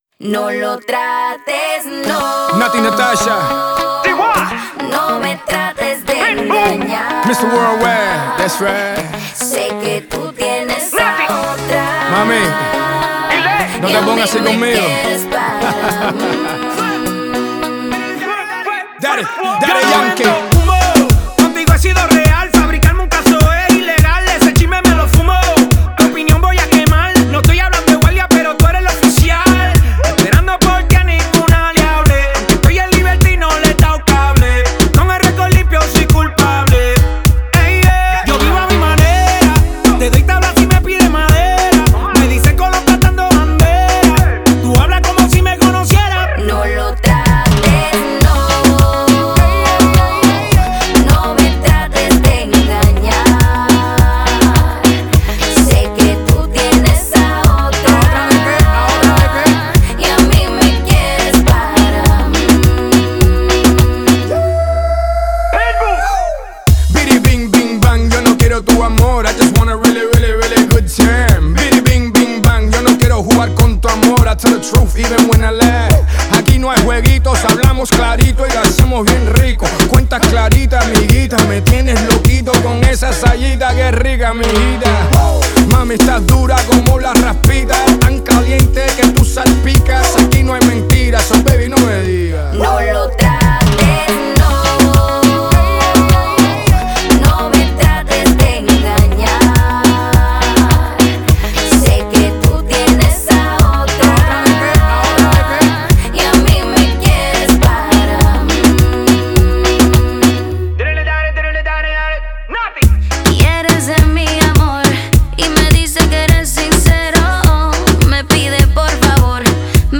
Genre: Rap,Pop, Latin Music.